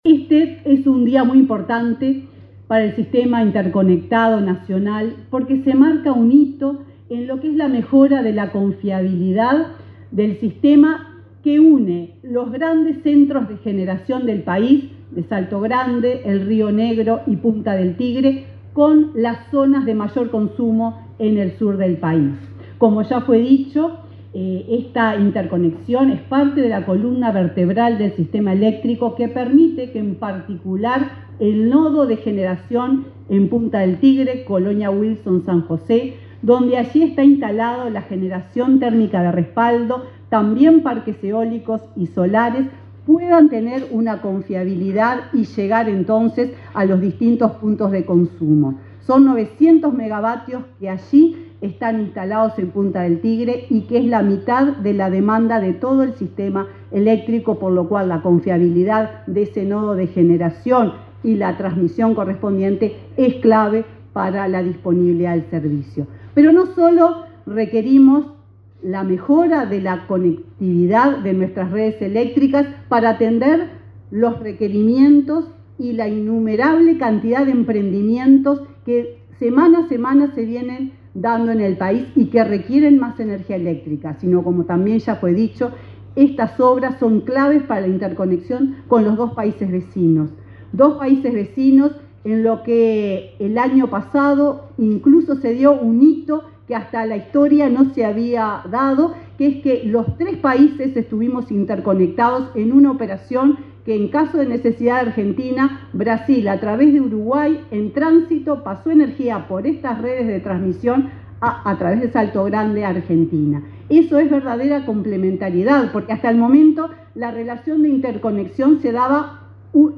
Palabras de autoridades en acto de UTE en Florida
La presidenta de UTE, Silvia Emaldi, y la ministra de Industria, Elisa Facio, fueron las oradoras centrales del acto de inauguración de la subestación